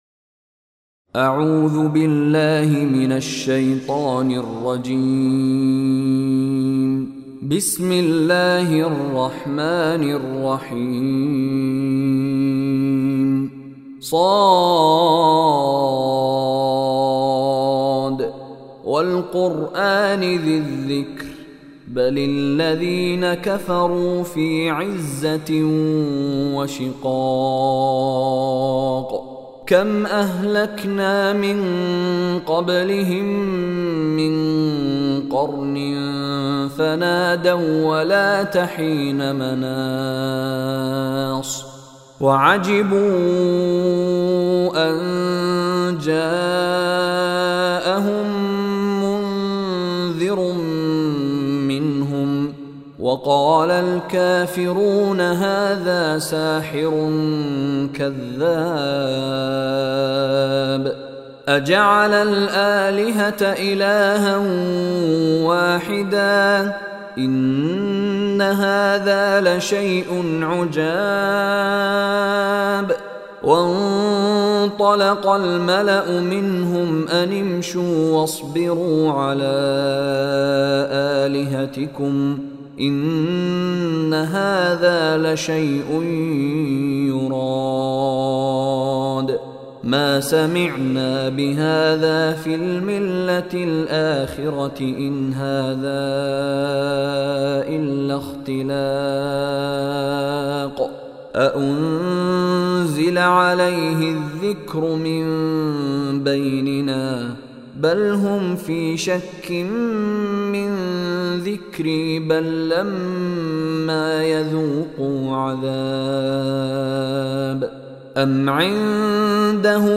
Surah Saad is 38 chapter of Holy Quran. Listen online and download beautiful recitation / tilawat of Surah Sad in the beautiful voice of Sheikh Mishary Rashid Alafasy.